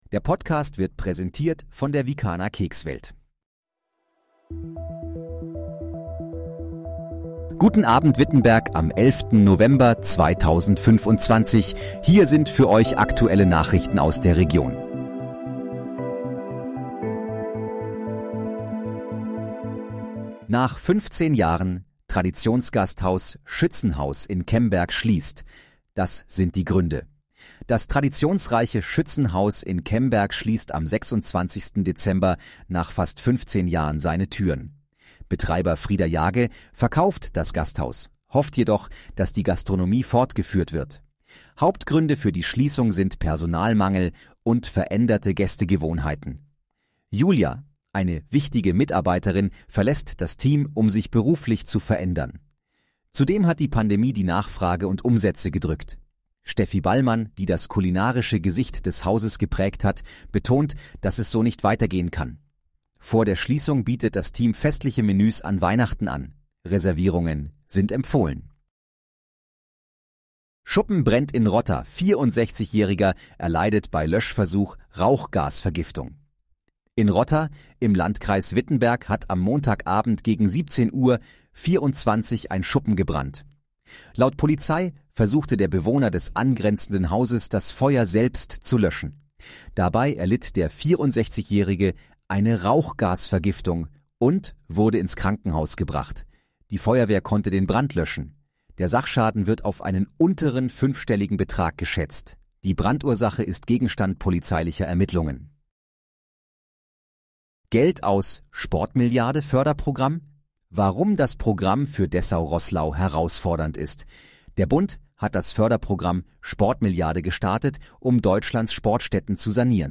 Guten Abend, Wittenberg: Aktuelle Nachrichten vom 11.11.2025, erstellt mit KI-Unterstützung
Nachrichten